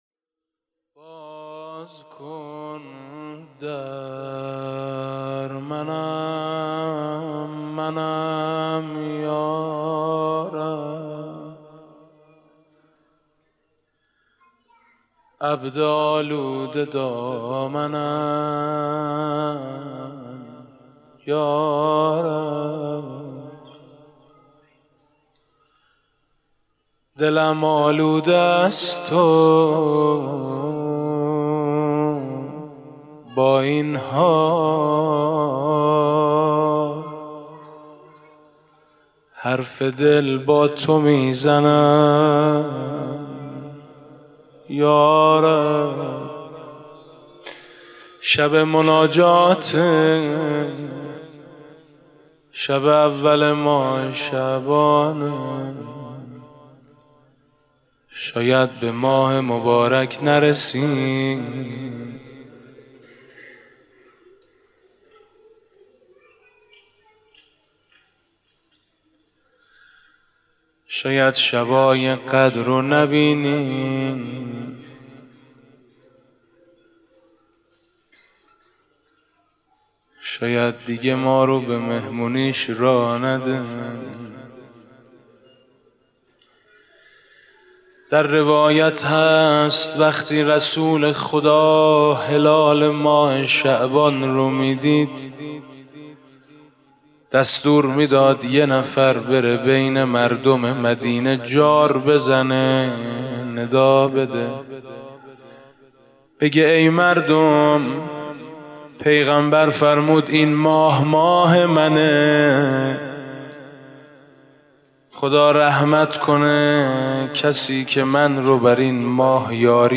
7 اردیبهشت 96 - شهدای گمنام - مناجات - باز کن در